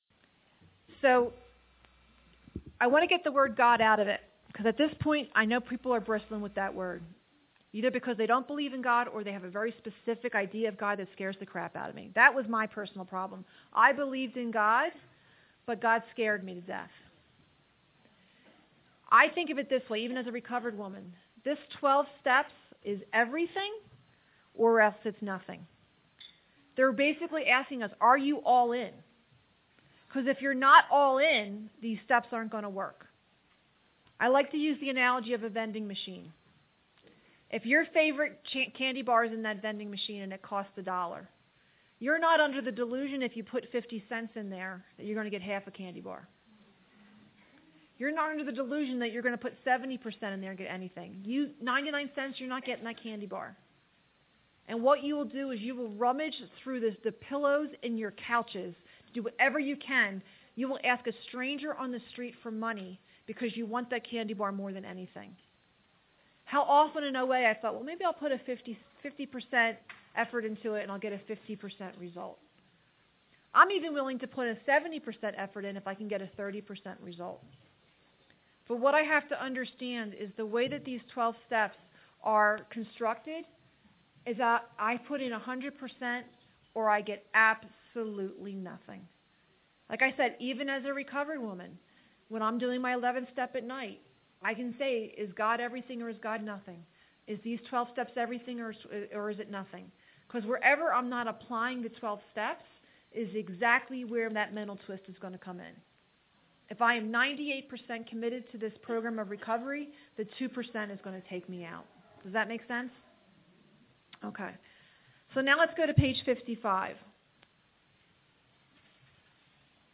A Big Book Retreat in Estes Park